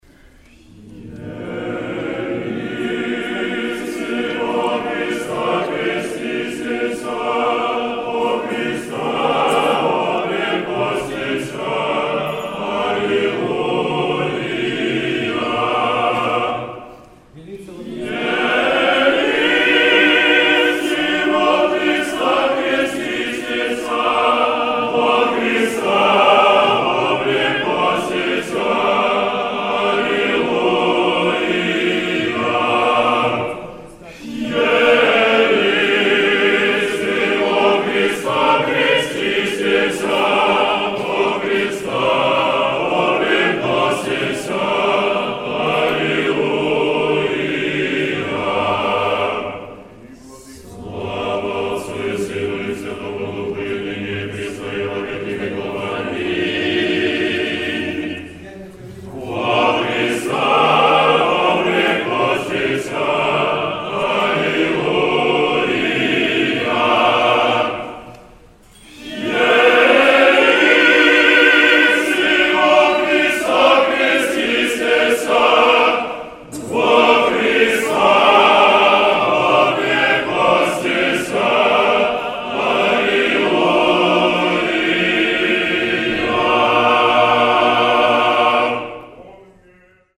In place of the trisagion hymn is sung, As many as have been baptized into Christ, Have put on Christ, Allelulia.